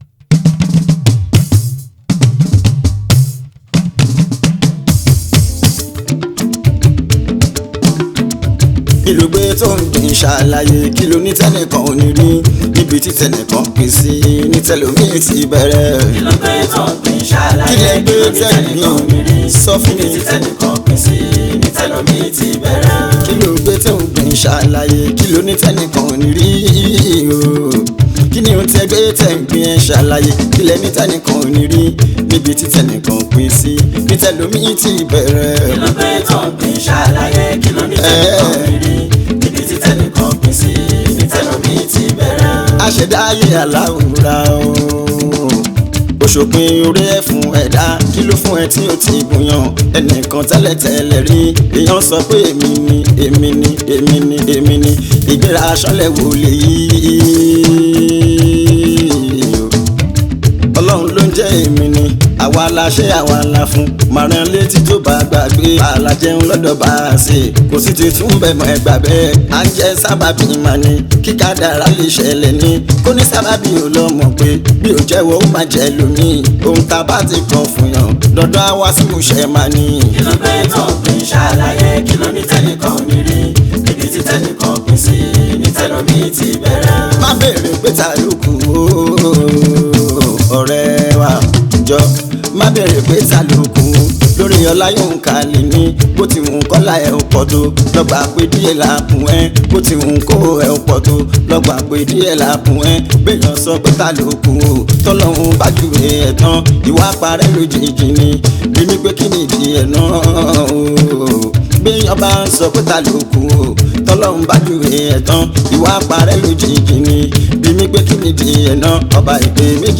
Prolific and high Profile Yoruba Islamic Music singer
Yoruba Fuji song
Fuji song